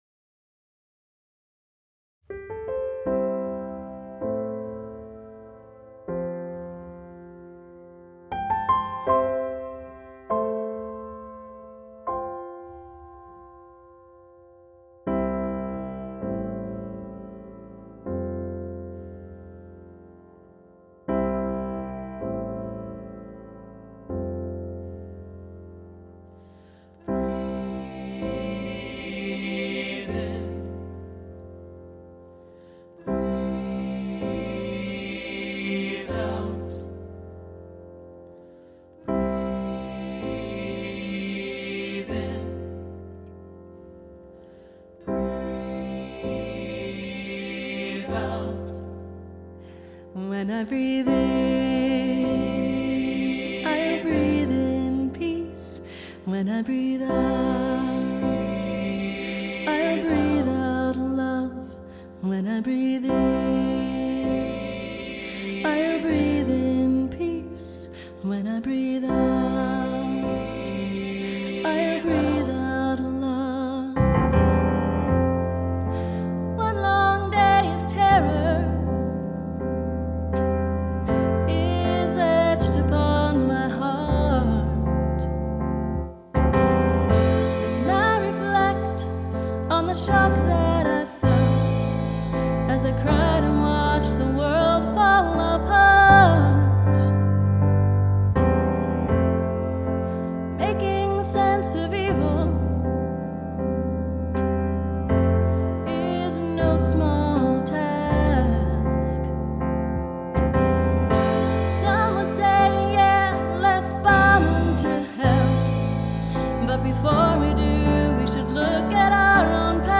for SATB choir.